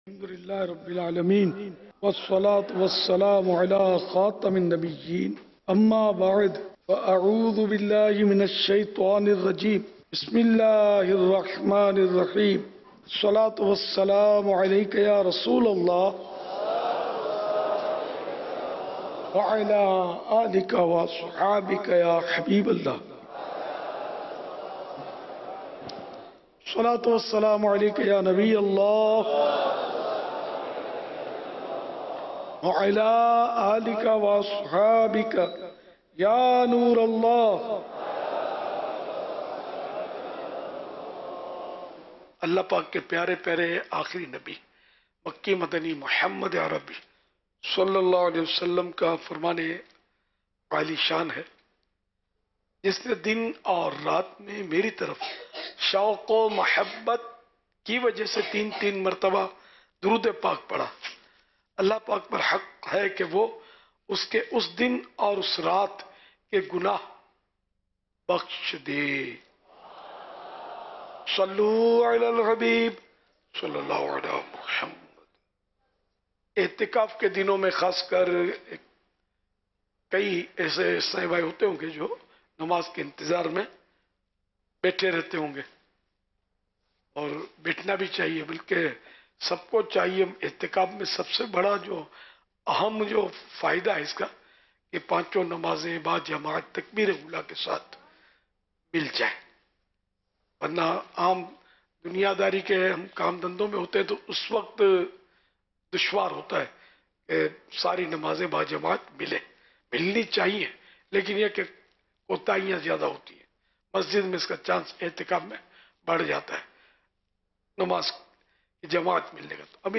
25 Ramzan UL Mubarak Namaz Fajr Ke Bad Ka Bayan - Ek Hath Ki Ungliyan Dusre Hath Ki Ungliyon Mein Dalna Kaisa?